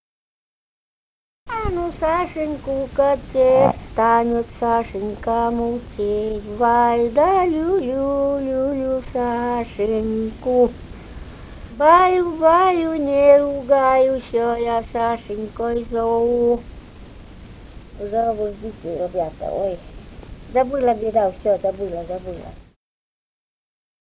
Живые голоса прошлого 156. «Стану Сашеньку качать» (колыбельная).